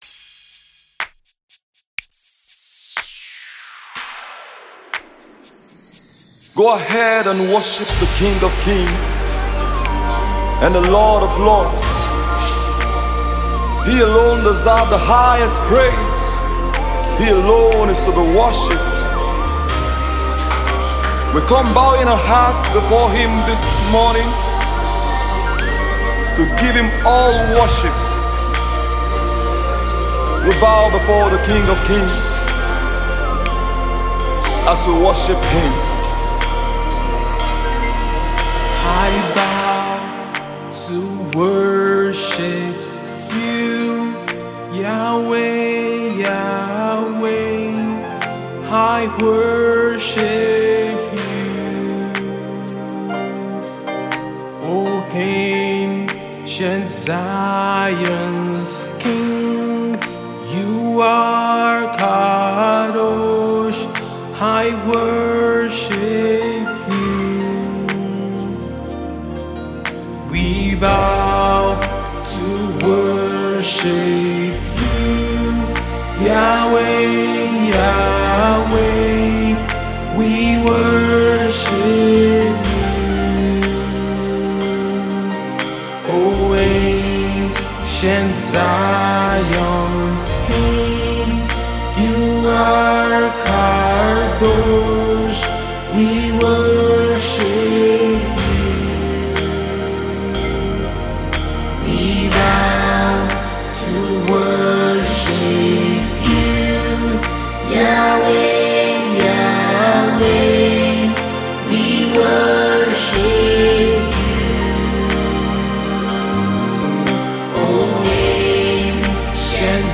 musicWorship